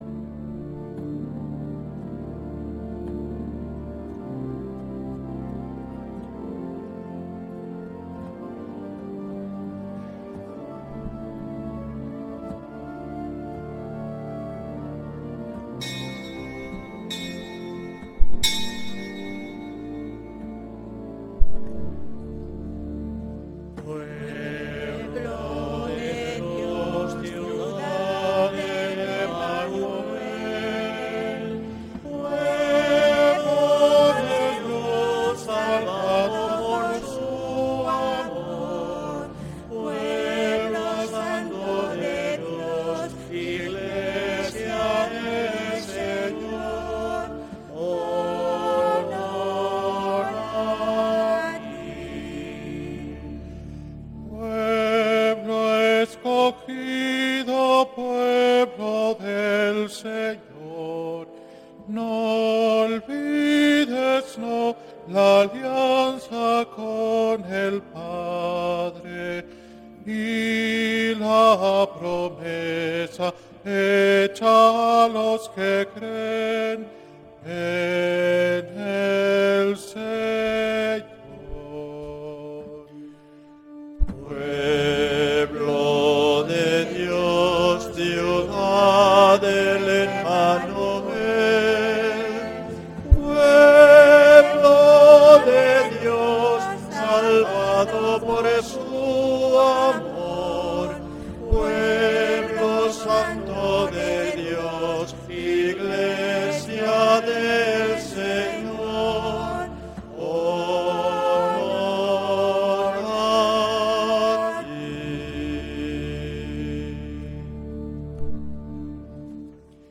Santa Misa desde San Felicísimo en Deusto, domingo 7 de septiembre de 2025